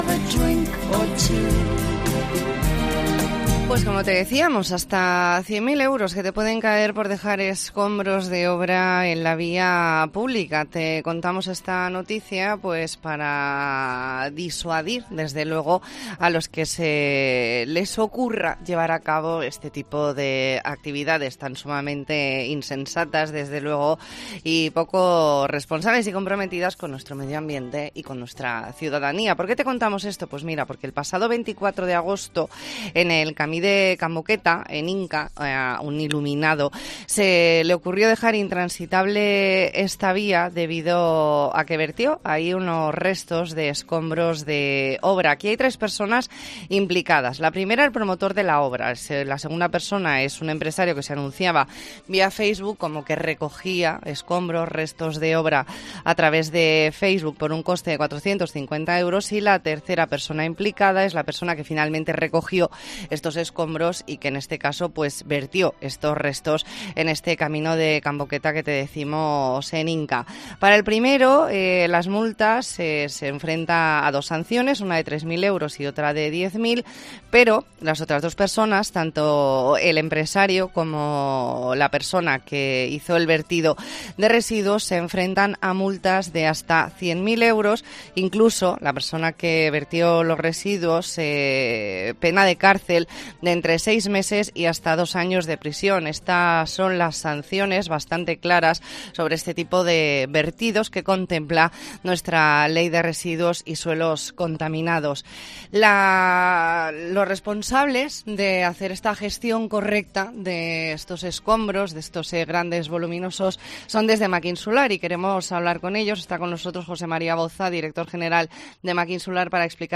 E ntrevista en La Mañana en COPE Más Mallorca, lunes 26 de septiembre de 2022.